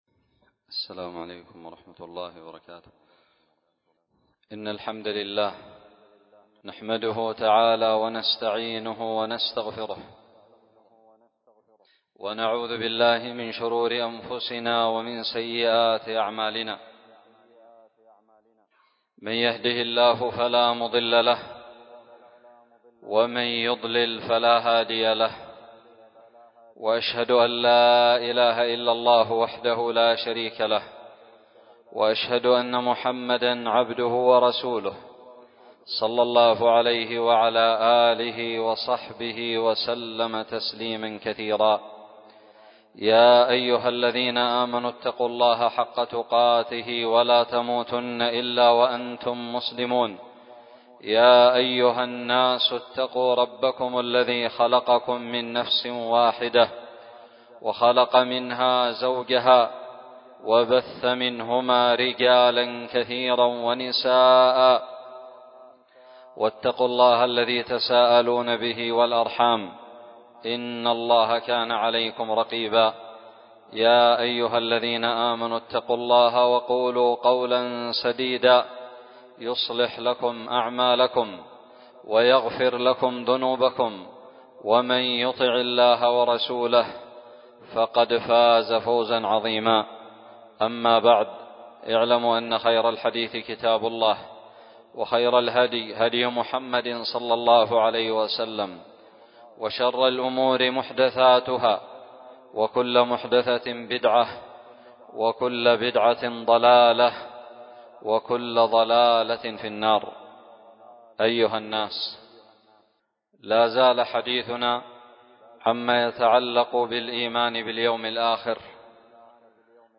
خطب الجمعة
ألقيت بدار الحديث السلفية للعلوم الشرعية بالضالع